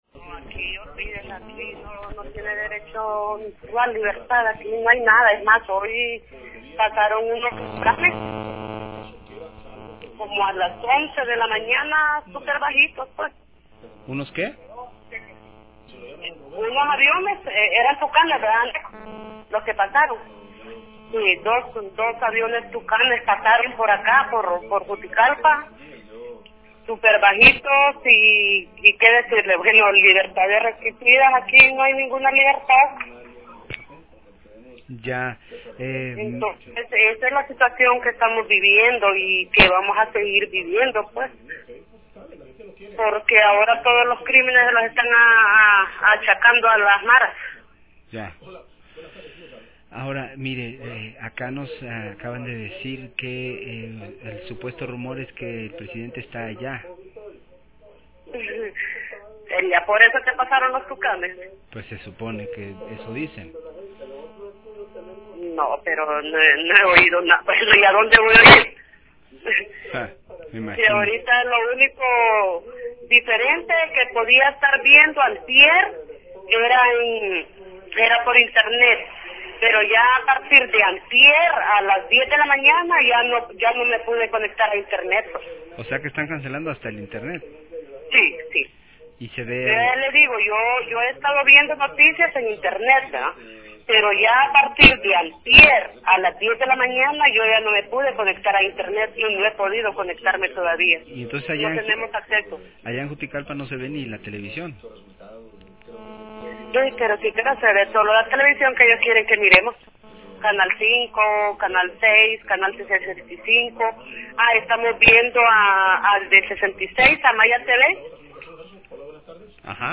Reporte desde Juticalpa, departemento de Olancho